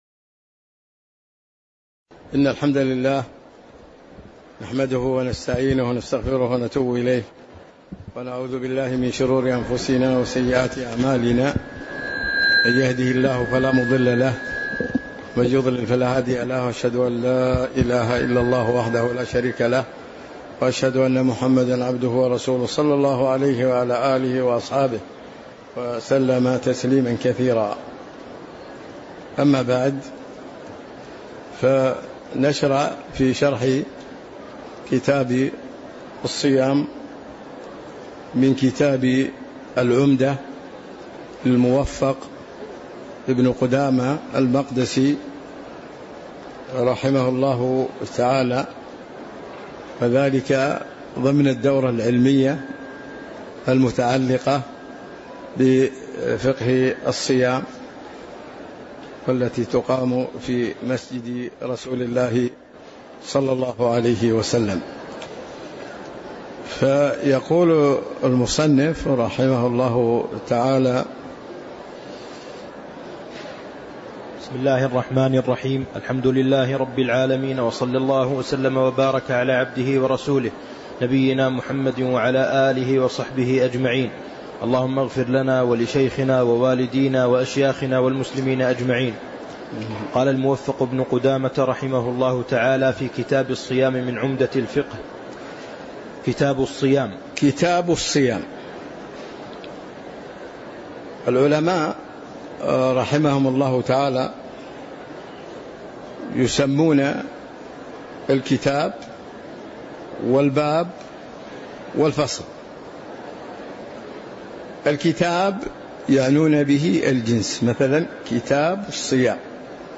تاريخ النشر ٢٠ شعبان ١٤٤٦ هـ المكان: المسجد النبوي الشيخ